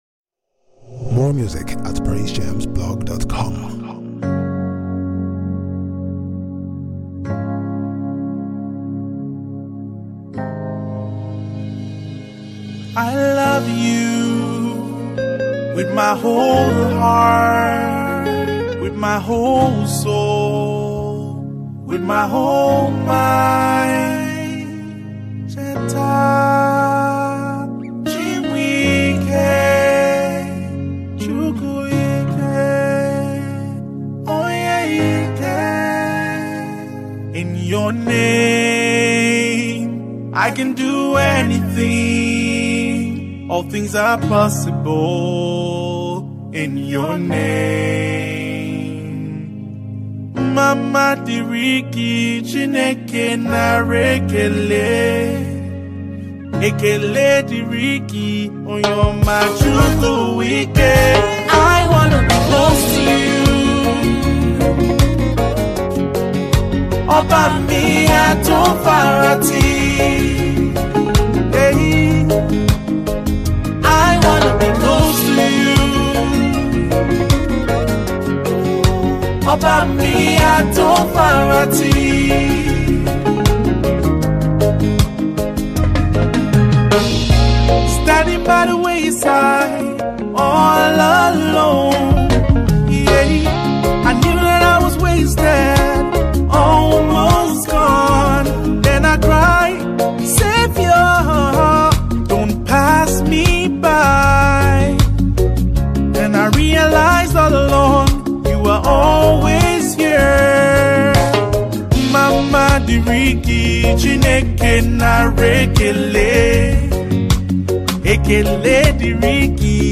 Nigerian gospel music minister